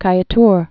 (kīĭ-tr)